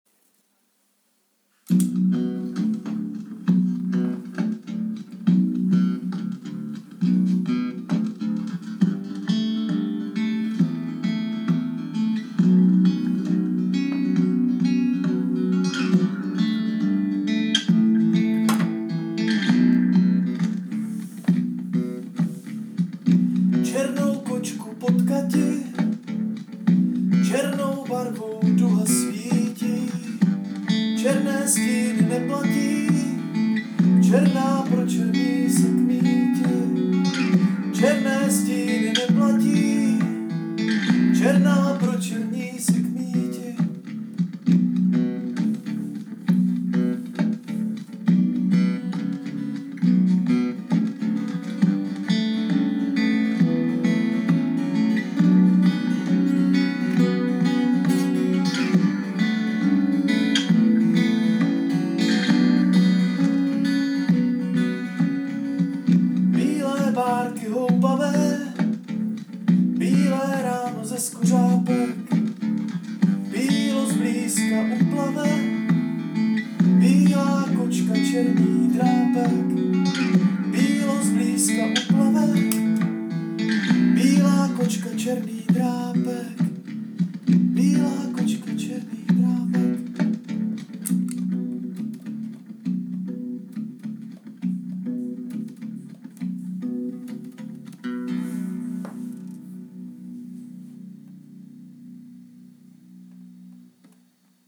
Anotace: Vkládám úplně první verzi zhudebněné, zahrané a zazpívané písně o černé kočce.
chytlavá :)